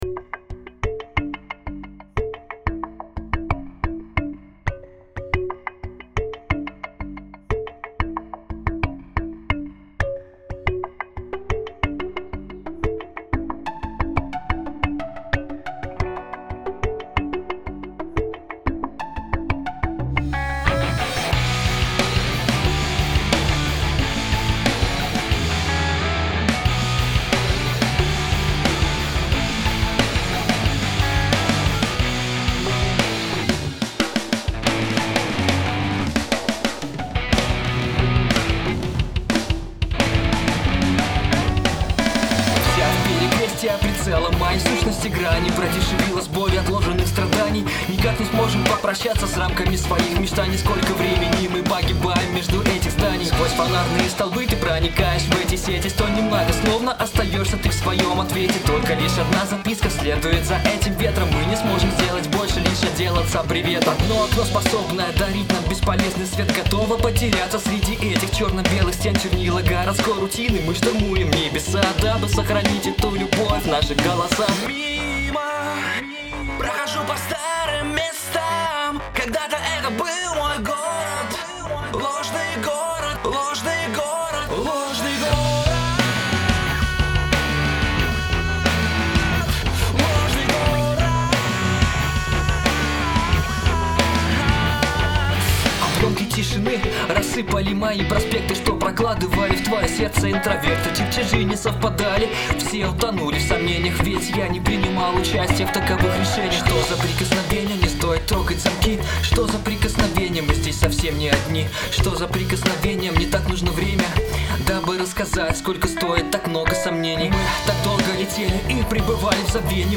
Господа, у меня такая проблема/ситуация: я автор-исполнитель, записываюсь дома (бас, гитары, синты - вживую, барабаны электронные).